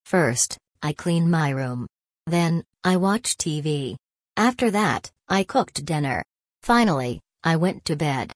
Pay close attention to the pause after each comma: